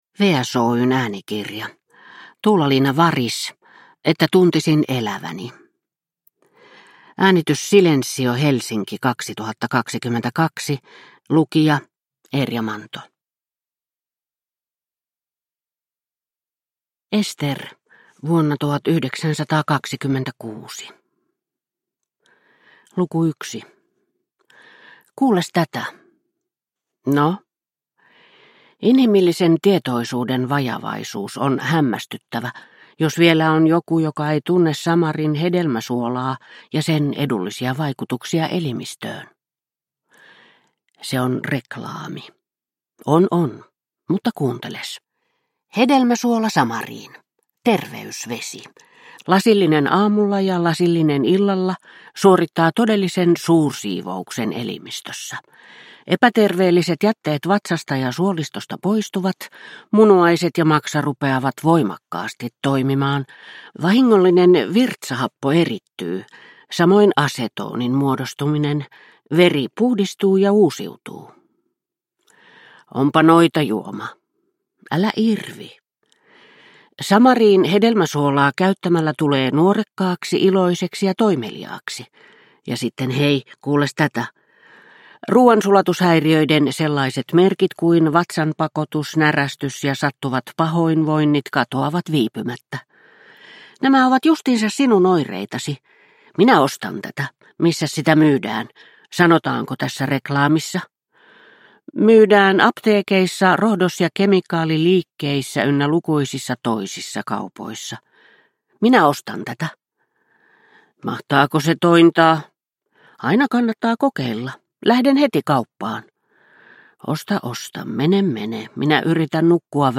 Että tuntisin eläväni – Ljudbok – Laddas ner